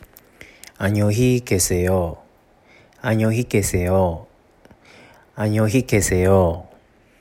ちなみに、発音ってどうなってるの？と今すぐ使いたい人は思うと思いますので、その方に向けて発音も録音したので、もしよろしければチェックしてみてください。
[aside type=”boader”] 【韓国語で”さようなら”の発音】